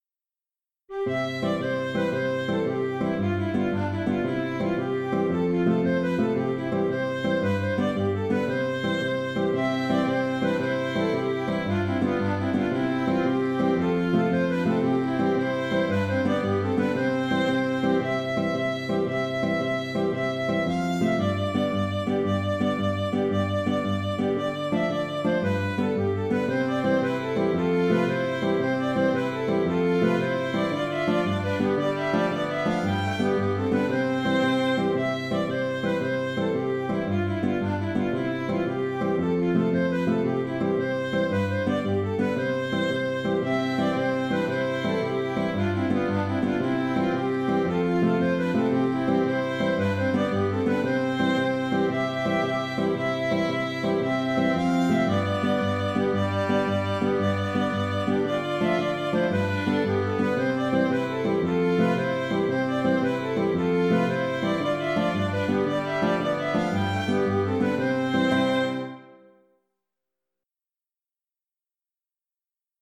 Cumberland reel (Danse écossaise) - Musique irlandaise et écossaise
En attendant, le contrechant permet de varier les interprétations..